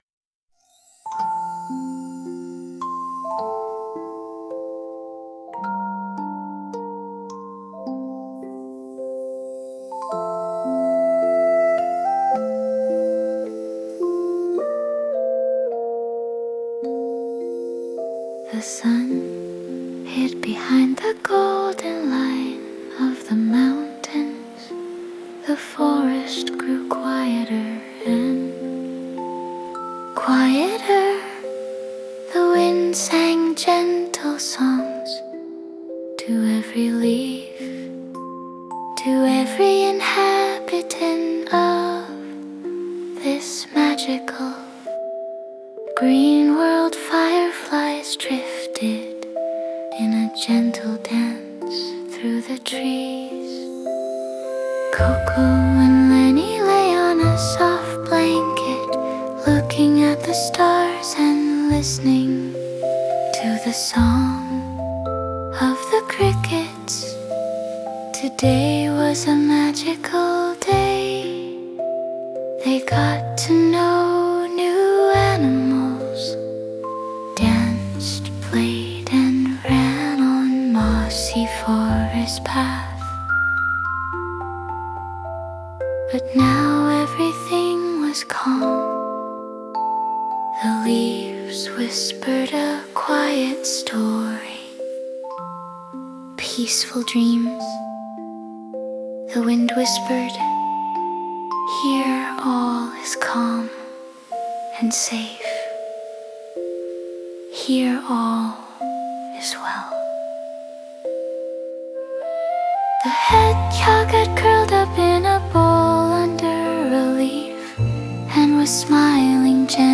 a-bedtime-fairytale-bKjrGFr90yjwdK6X.mp3